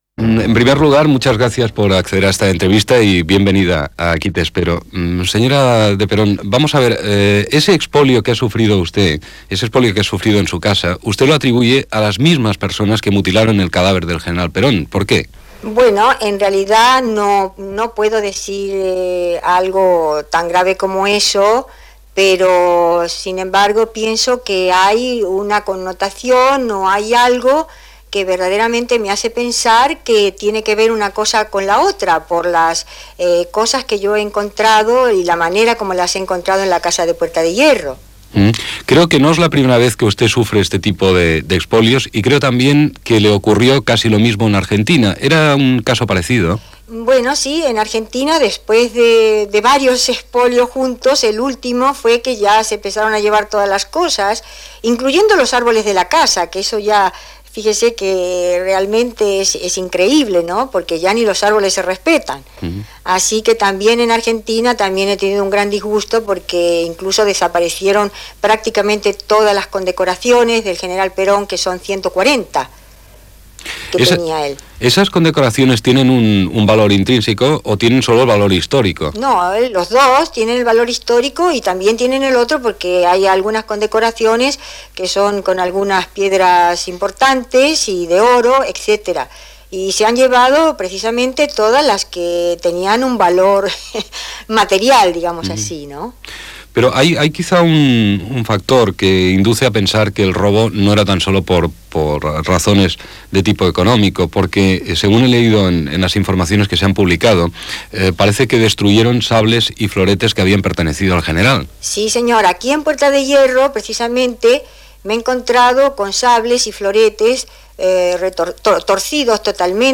Entrevista a María Estela Martínez de Perón, vídua del general Perón i coneguda com a "Isabelita", sobre el robatori patit a la seva casa de Puera De Hierro i el règim peronista